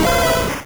Cri de Caninos dans Pokémon Rouge et Bleu.